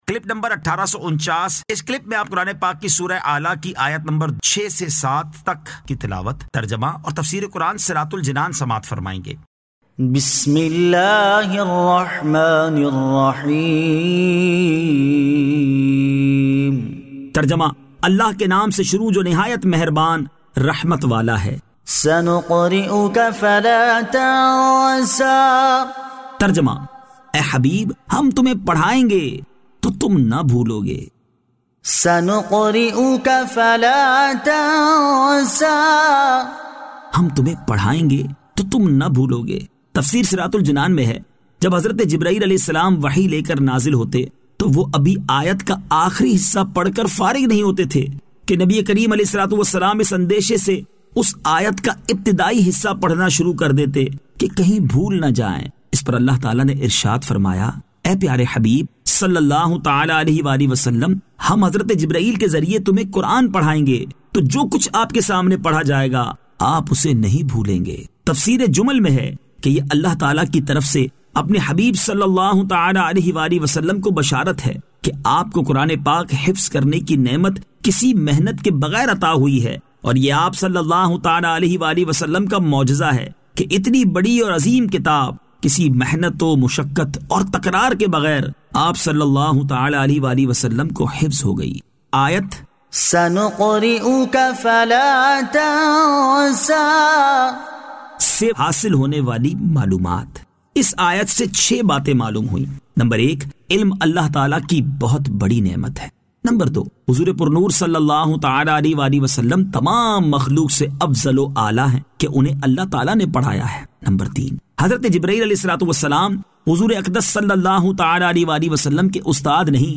Surah Al-A'la 06 To 07 Tilawat , Tarjama , Tafseer